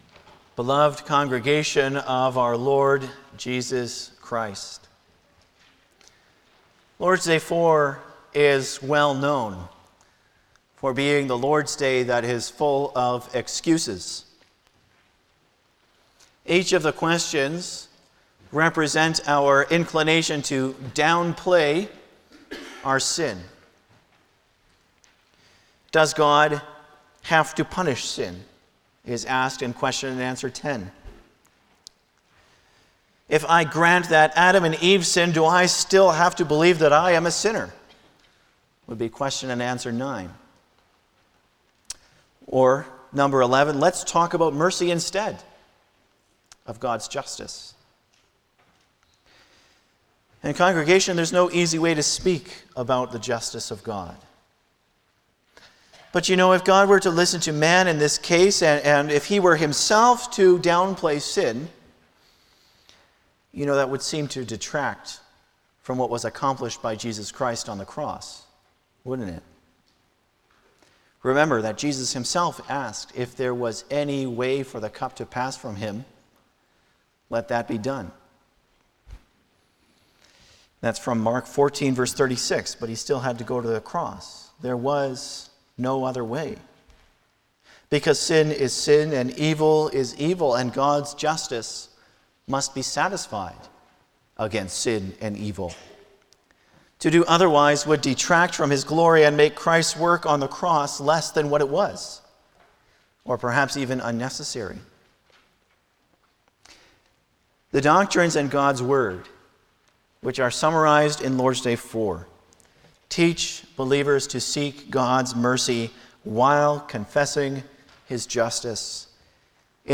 Passage: Lord’s Day 4 Service Type: Sunday afternoon
07-Sermon.mp3